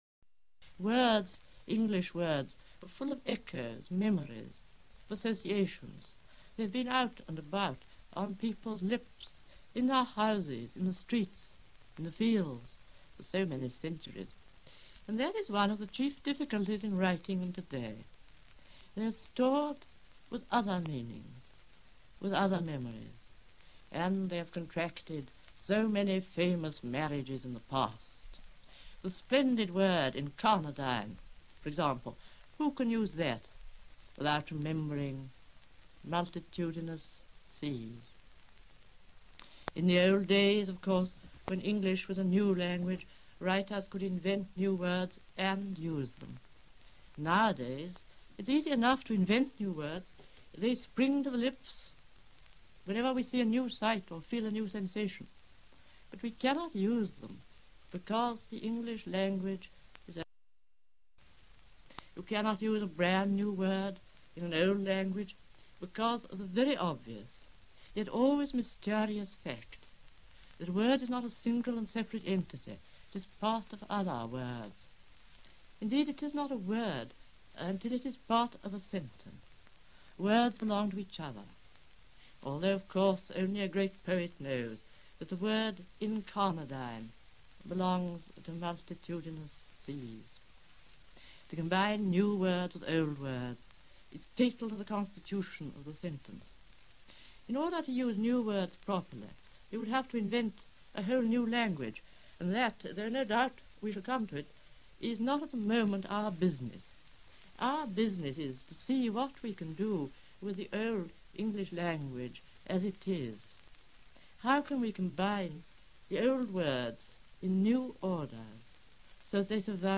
[The audio above is a recording of Virginia Wolfe waxing philosophic about words and the English language]
the-recorded-voice-of-virginia-woolf.mp3